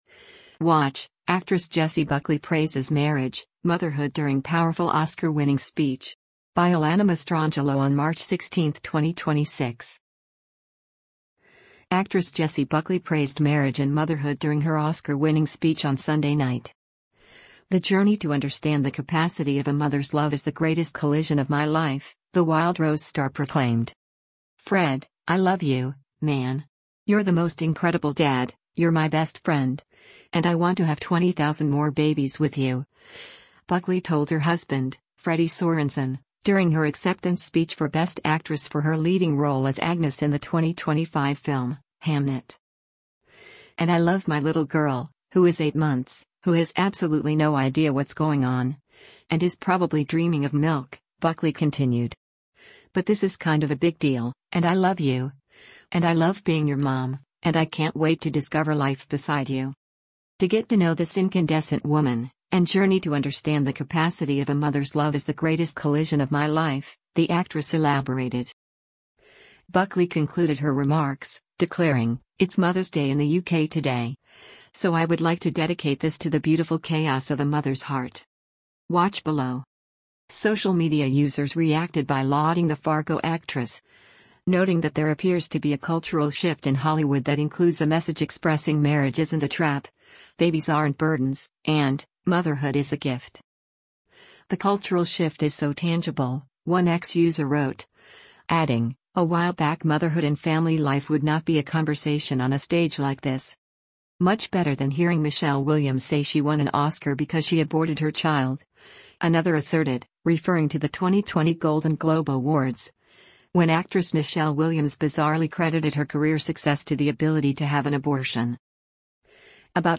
Actress Jessie Buckley praised marriage and motherhood during her Oscar-winning speech on Sunday night.